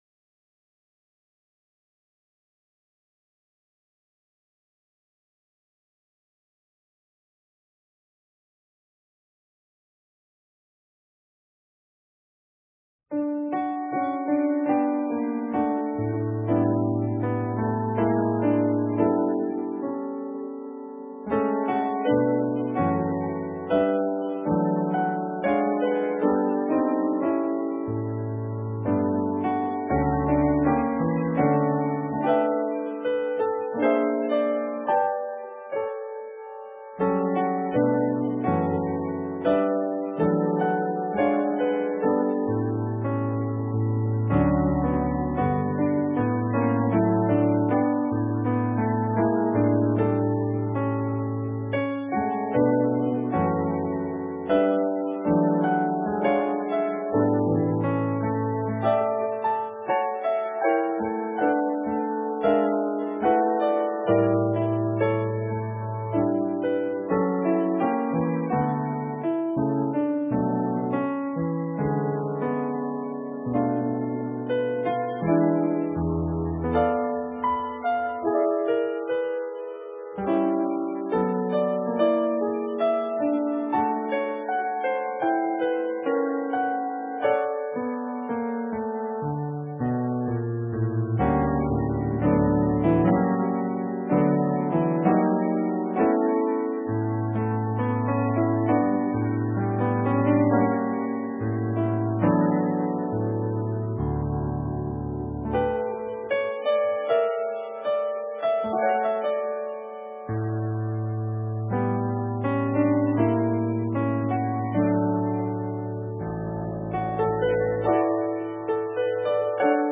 Sermon:What Can Override Compassion? part I - St. Matthew's UMC
Note: The prelude begins 15 minutes into the video but is at the beginning of the audio file